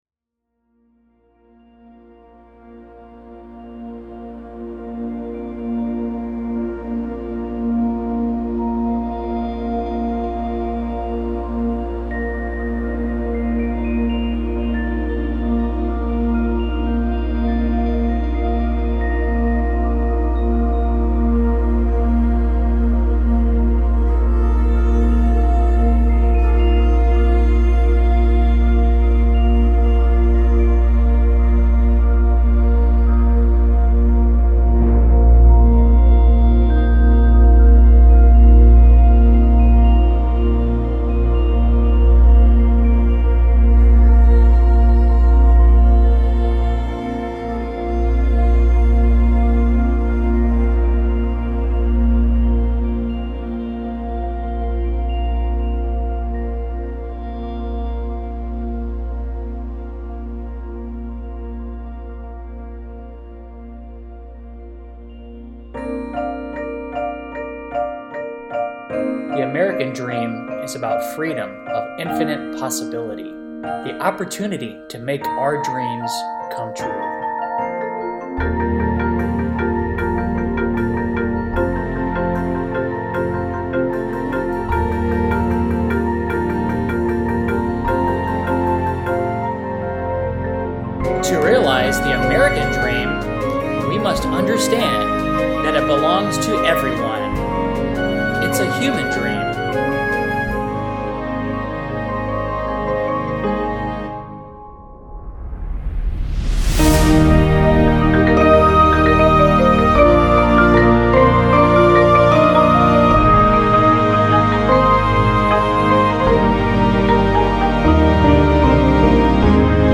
Snare Drum
Synthesizer Marimba
Vibraphone